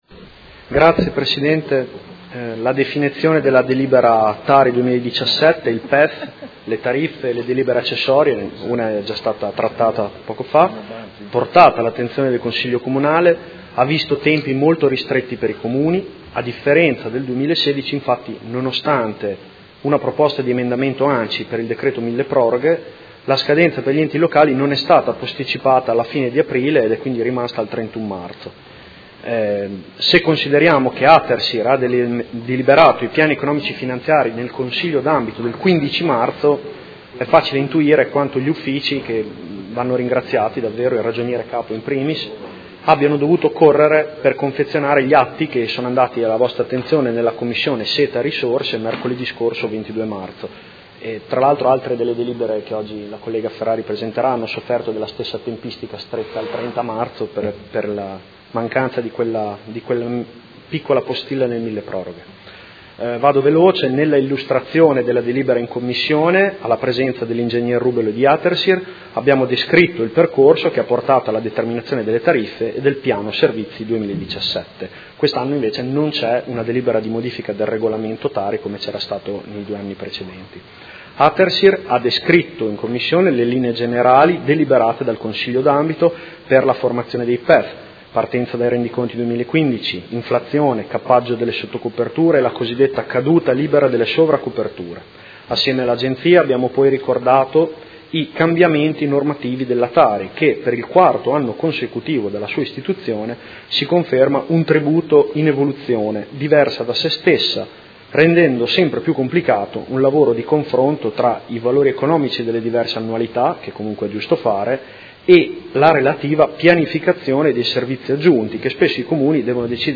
Seduta del 30/03/2017. Proposta di deliberazione: Tassa sui rifiuti (TARI) anno 2017: Approvazione del Piano Economico Finanziario, delle tariffe, del piano annuale delle attività per l’espletamento dei servizi di gestione dei rifiuti urbani e assimilati